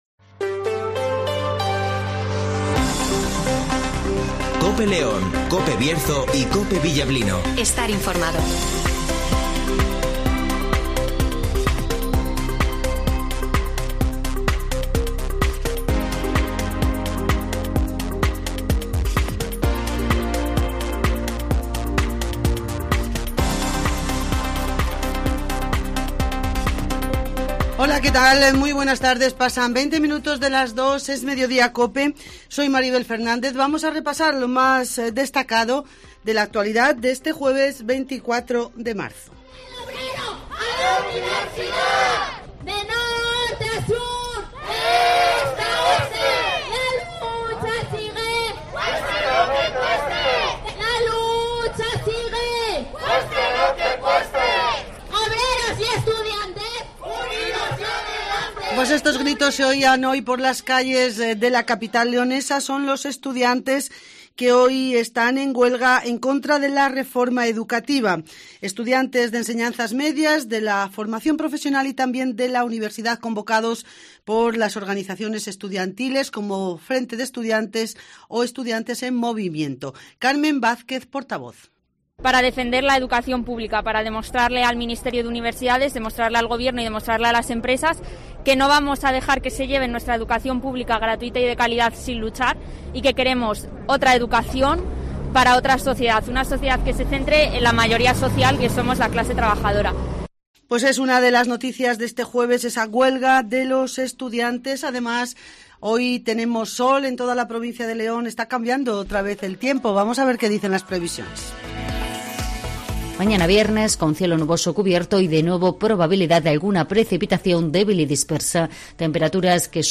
- Gritos Huelga Estudiantes